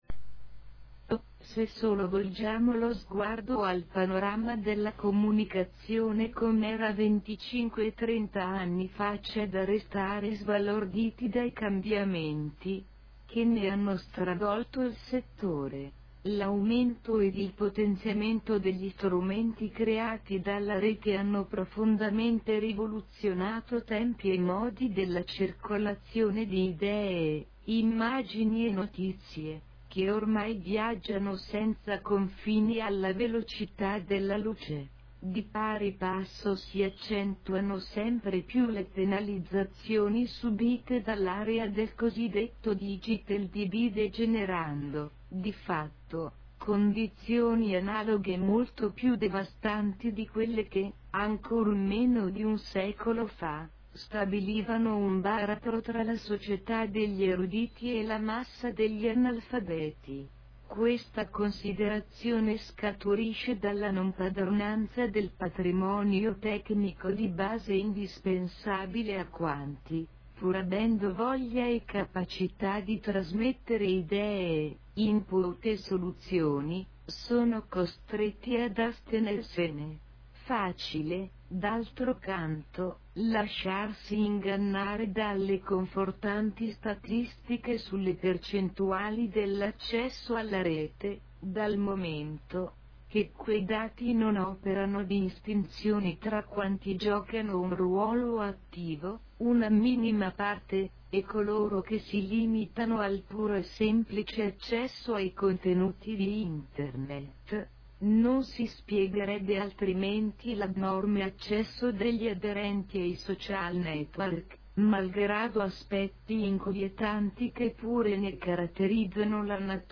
(OPZIONE AUDIO in VOCE SINTETICA)